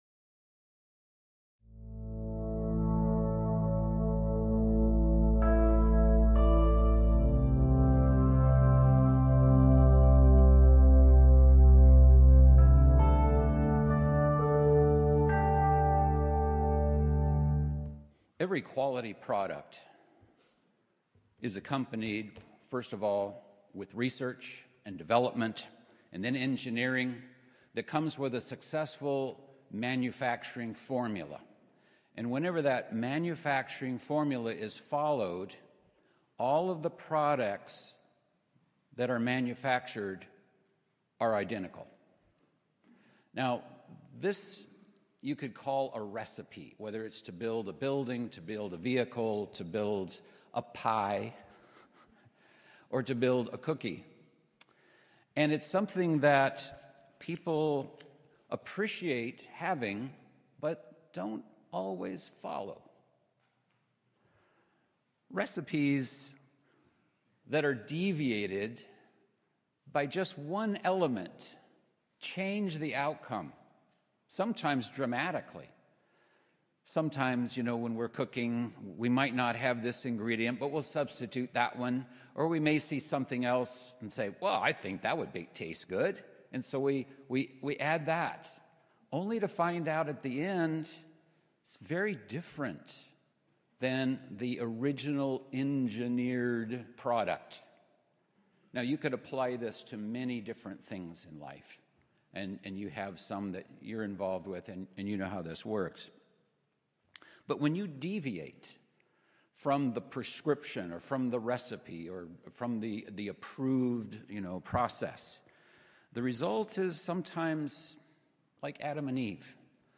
This sermon also reviews God's prescription for us to carefully observe the Passover on Nisan 14.